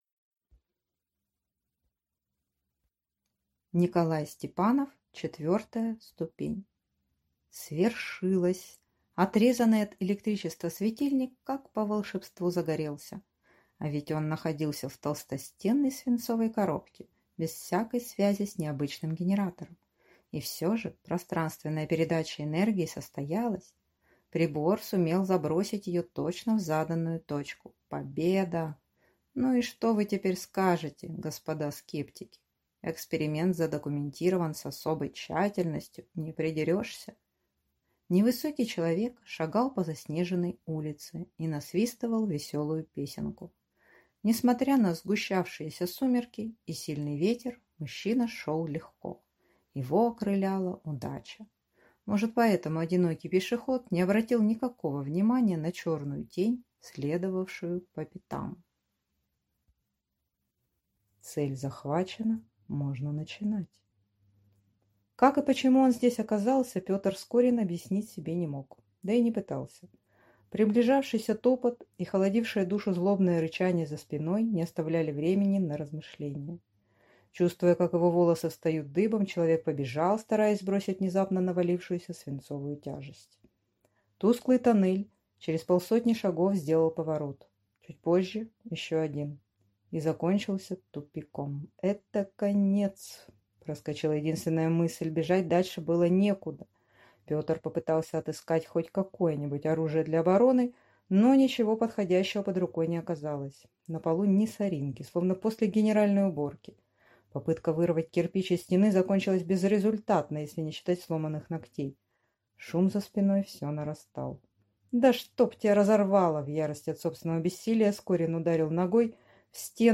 Аудиокнига Четвертая ступень | Библиотека аудиокниг
Прослушать и бесплатно скачать фрагмент аудиокниги